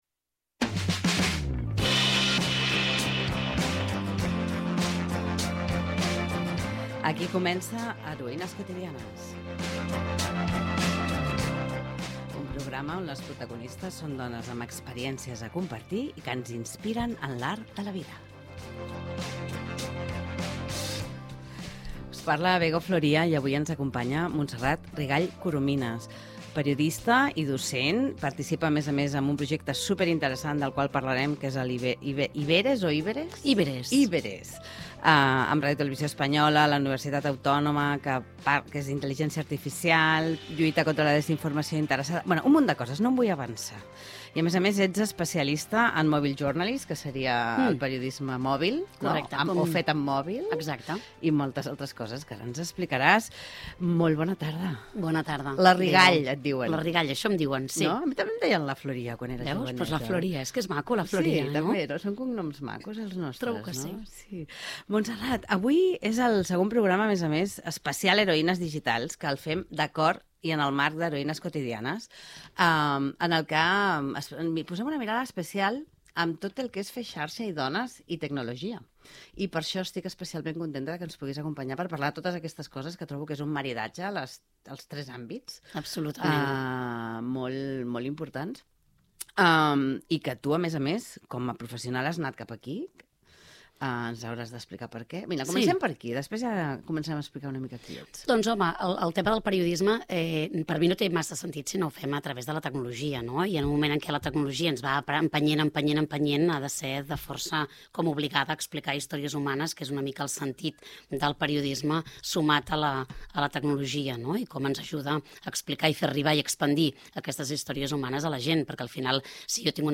Presentació i entrevista